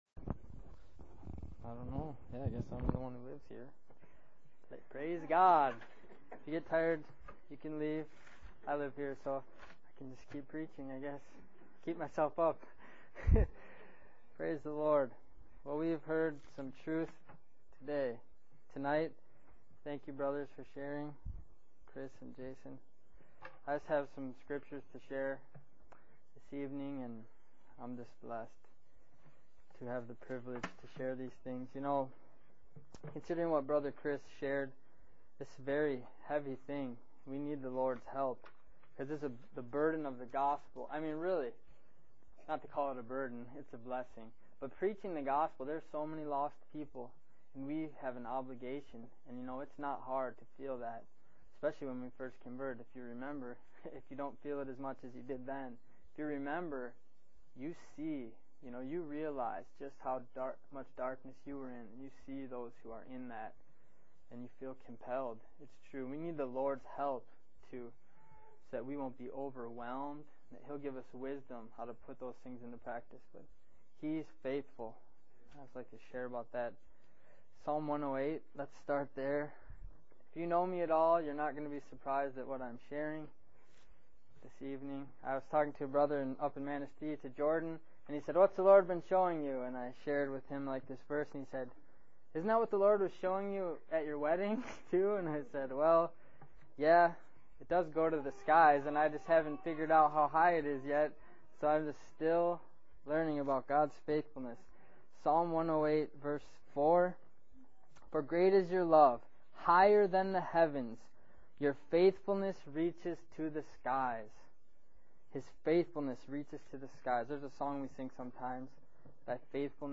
Amen